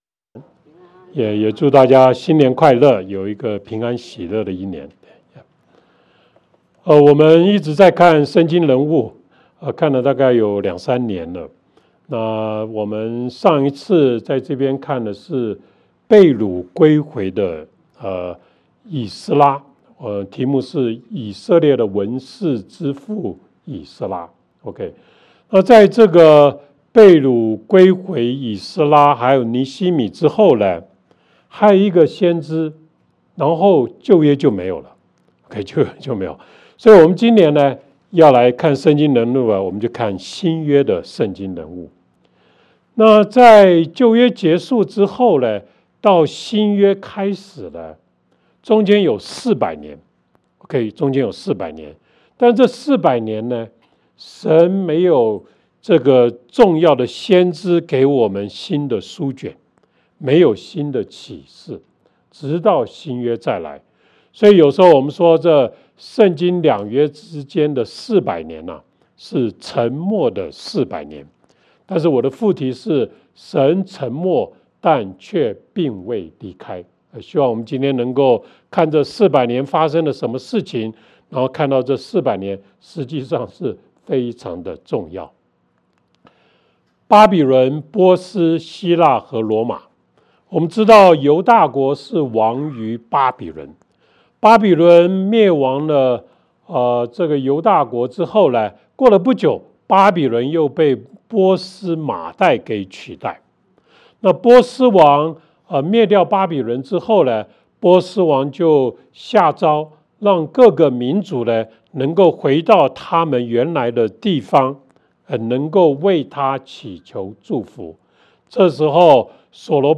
主頁 Home 關於我們 About Us 小組 Small Groups 事工 Ministry 活動 Events 主日信息 Sermons 奉獻 Give 資源 Resources 聯絡我們 Contact 聖經兩約之間四百年(神沉默，卻並未離開)